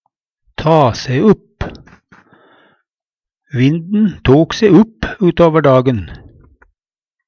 ta se upp - Numedalsmål (en-US)